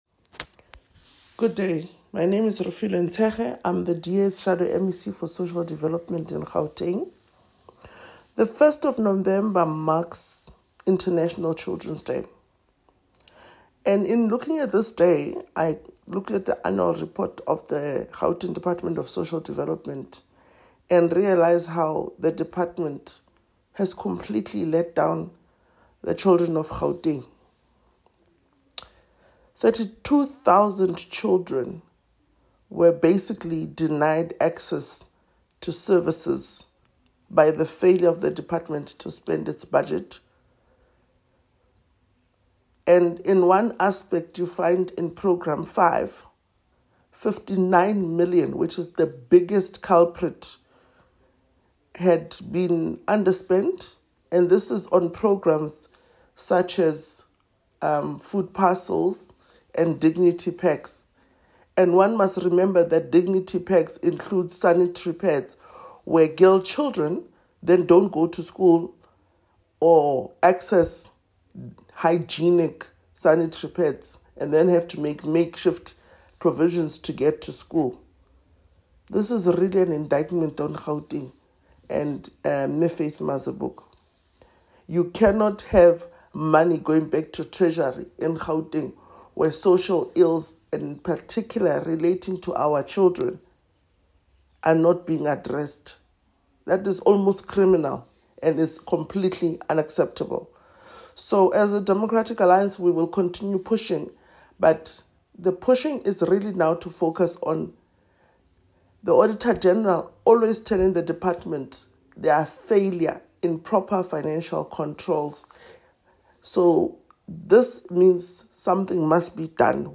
soundbite by Refiloe Nt’sekhe MPL.